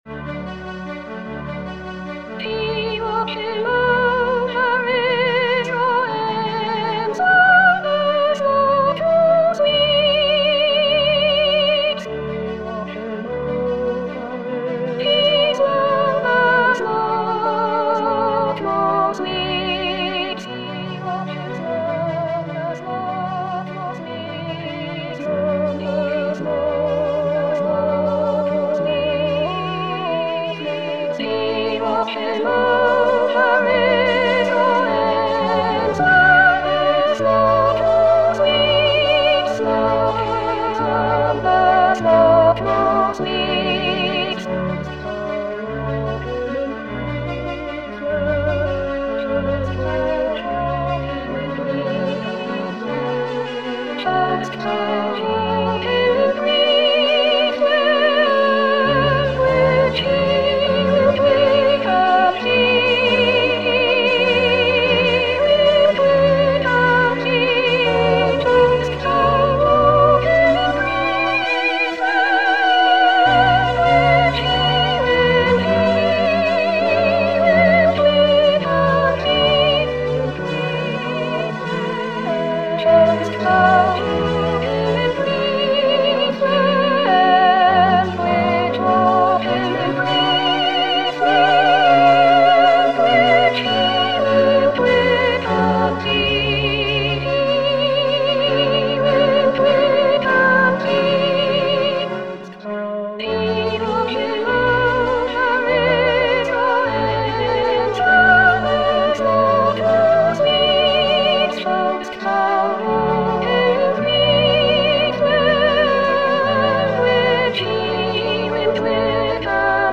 Soprano Soprano 1